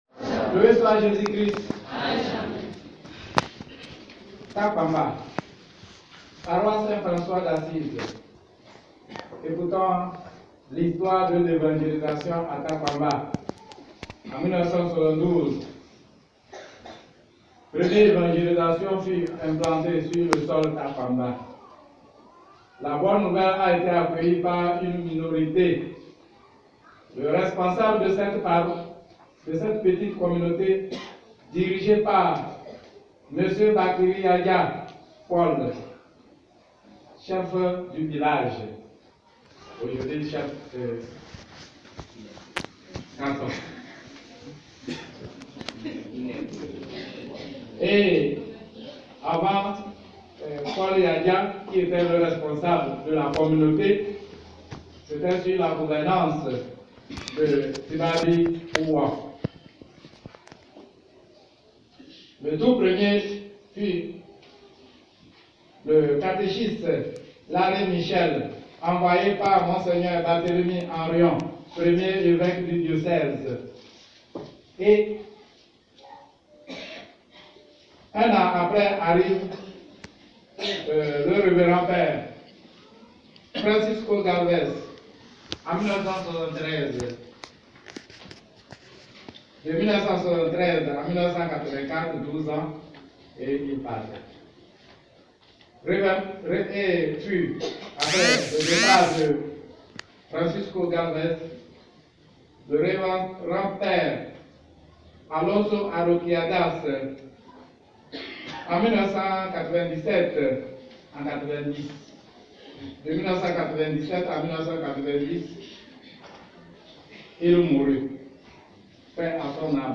La paroisse Saint François d’Assise de Takpamba a rendu grâce au Seigneur, le dimanche 16 février 2020, pour ses 25 ans d’existence.